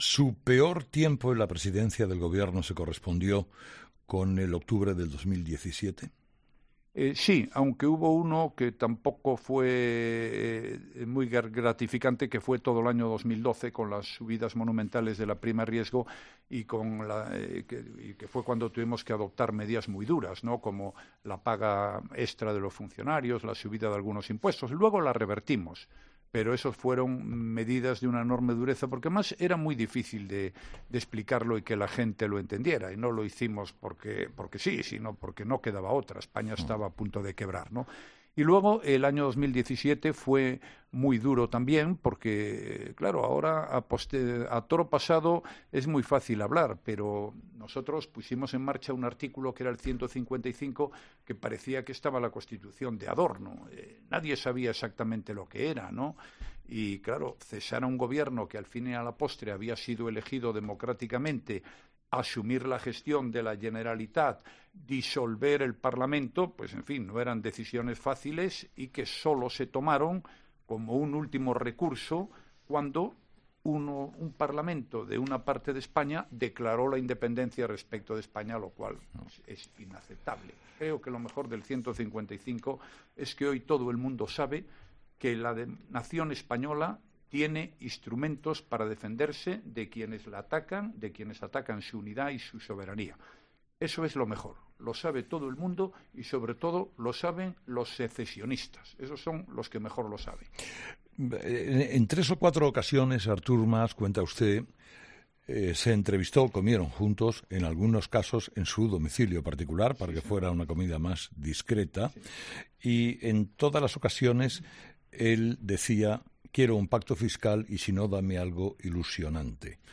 Así lo ha admitido el expresidente del Gobierno durante su entrevista este martes con Carlos Herrera con motivo de la publicación de su libro "Una España mejor", donde cuenta desde un punto de vista personal su etapa al frente del Ejecutivo.